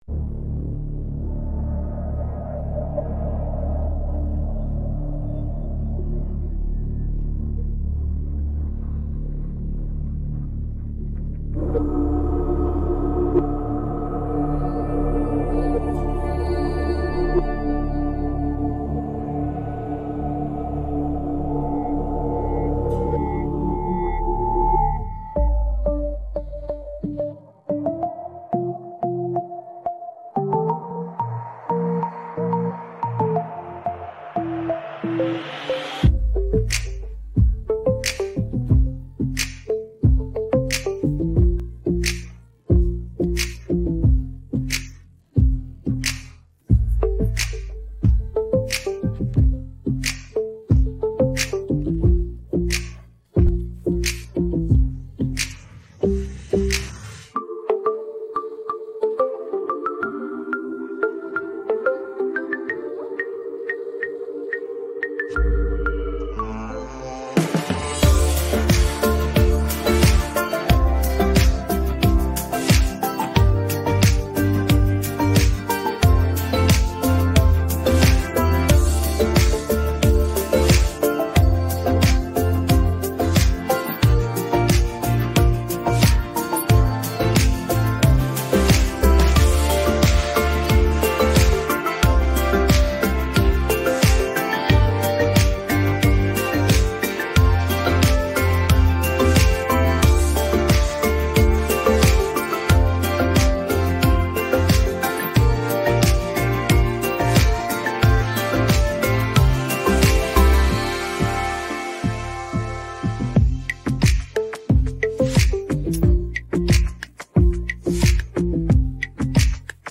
українське караоке